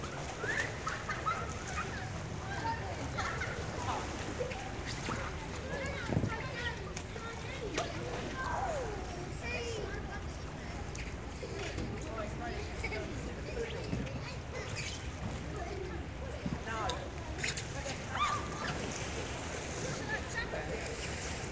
pool.ogg